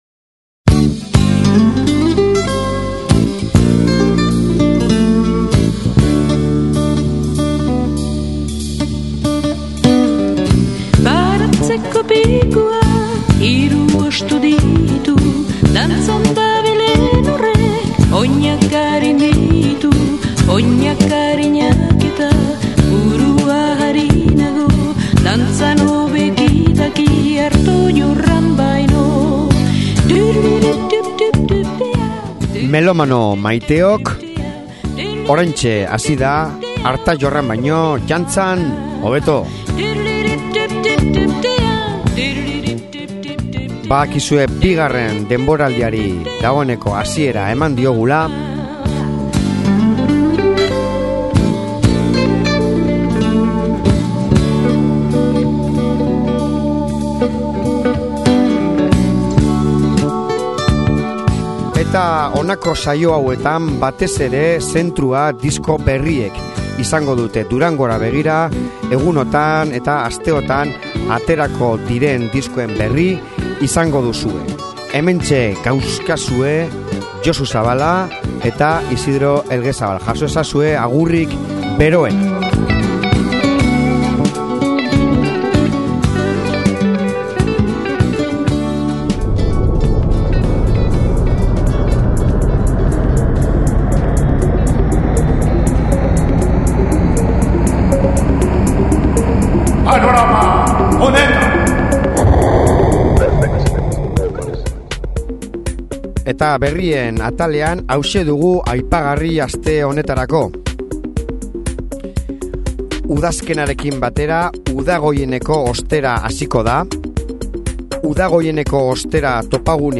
Gose III lanaren aurkezpenean izan ginen soziedade gastronomiko batean… guzti horren kronika gaur 2. denboraldiari hasiera emateko…ondo etorri!